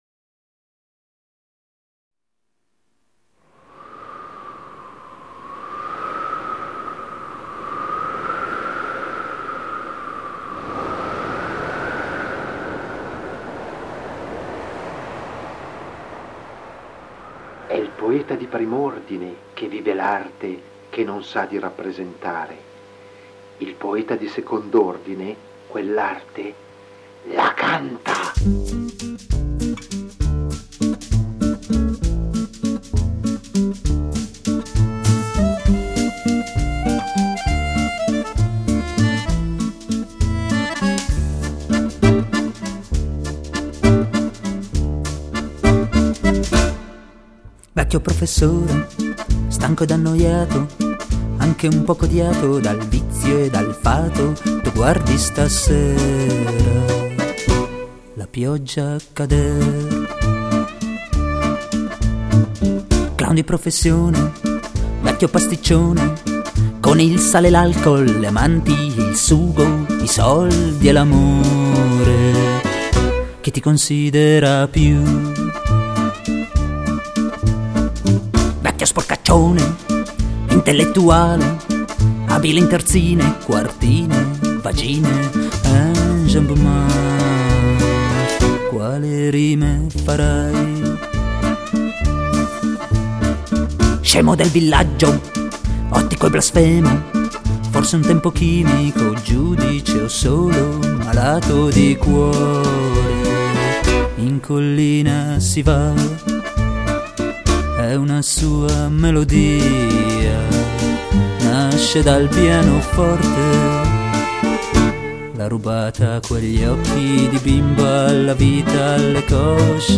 violoncello
batteria
basso elettrico fretless, contrabbasso
chitarra acustica, elettrica
violino
fisarmonica
voce, chitarra classica/acustica